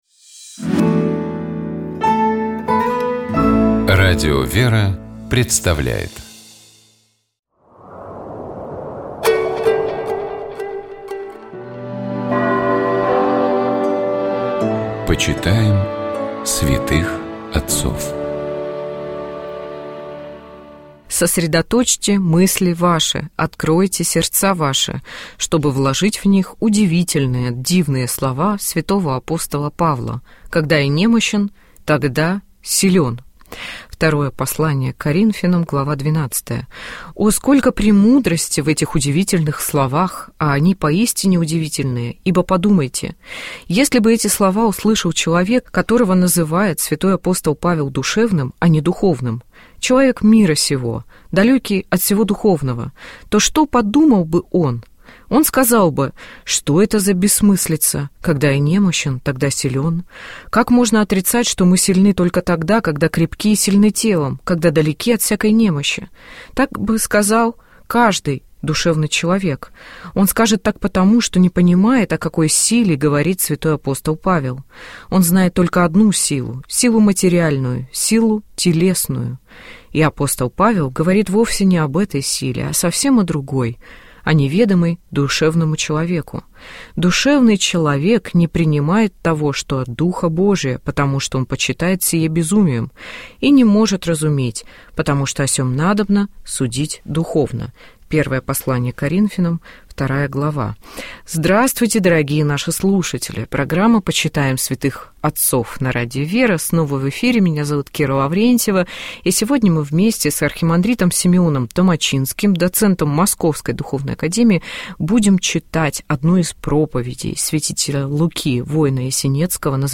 Каждую пятницу ведущие, друзья и сотрудники радиостанции обсуждают темы, которые показались особенно интересными, важными или волнующими